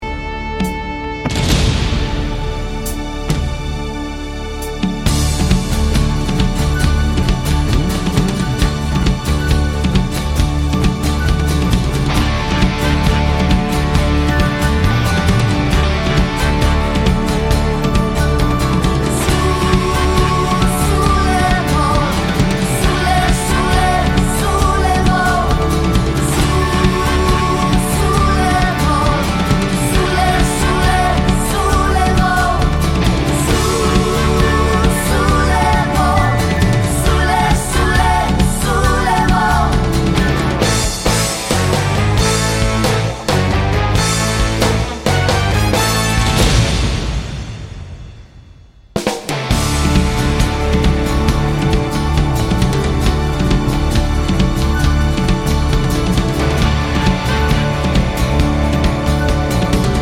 Full Live Version Pop (1970s) 5:01 Buy £1.50